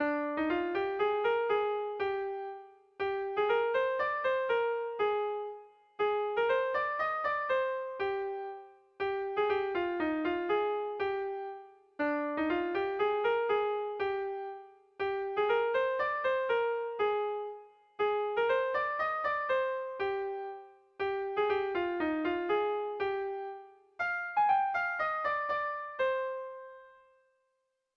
Dantzakoa
Kopla handiaren moldekoa